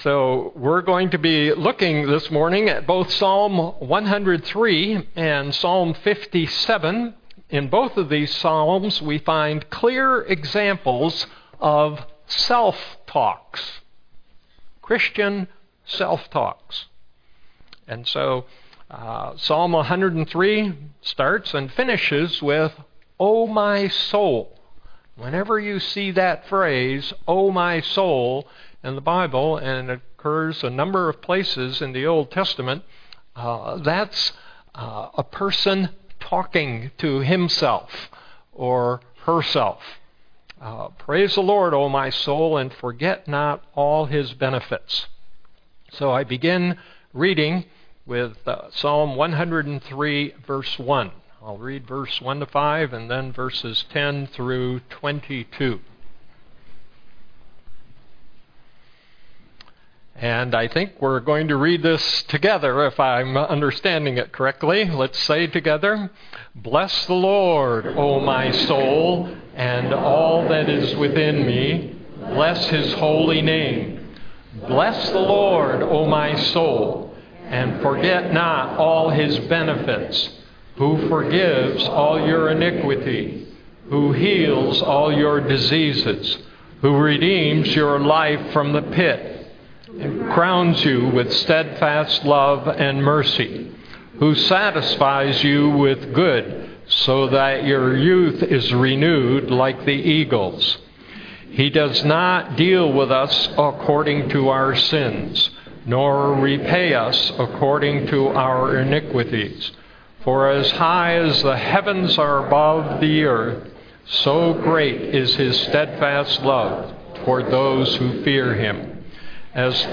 Text for Sermon: Psalm 103:15, 10-22; 57:1-4, 7-11